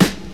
• Old School Boom Hip-Hop Snare One Shot F# Key 154.wav
Royality free snare drum tuned to the F# note. Loudest frequency: 1397Hz
old-school-boom-hip-hop-snare-one-shot-f-sharp-key-154-oGp.wav